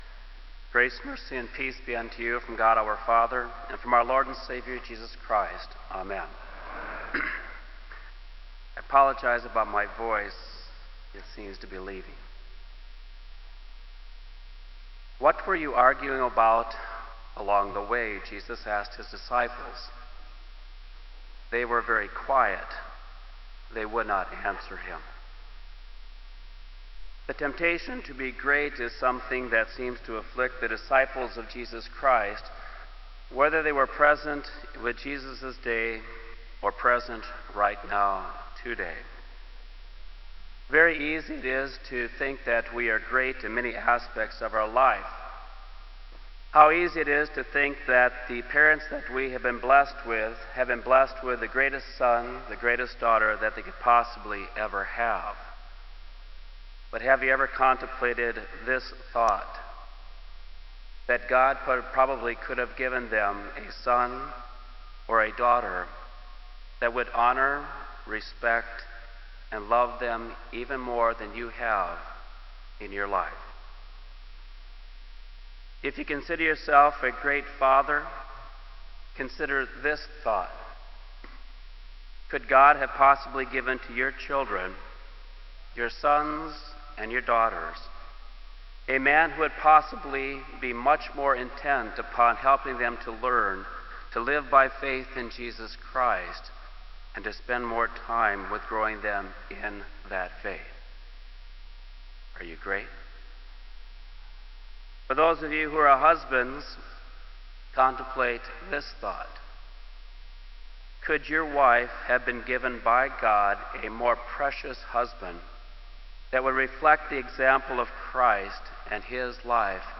Kramer Chapel Sermon - September 27, 2004